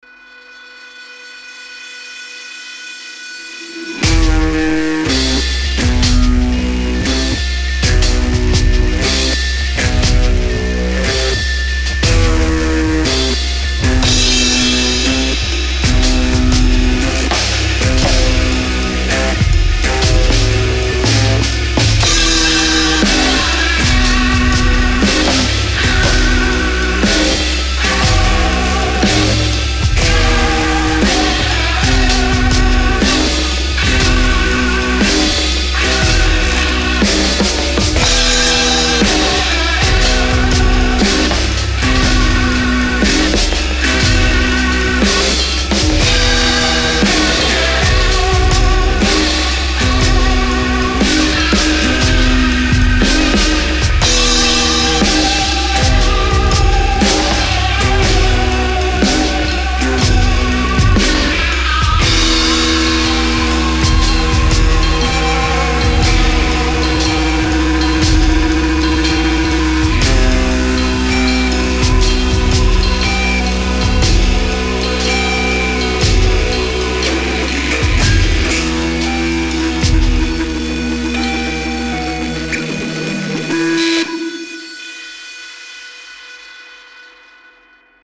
Хз что по басу сказать… он вроде и как-то заварен странно, но вроде и создает нужное движение и, честно говоря, себя как минимум получается обмануть, надеюсь и вас)) Сведения, собственно, нет, поэтому и демо, и, по-хорошему, будет время и силы, что-то от этого можно еще нарулить, кмк, поэтому и этюд Ну и мелодический выход конечно на окончание прям нафталиновый какой-то уж совсем… но тут уж подустал просто лепить – играть то тяжело пока очень))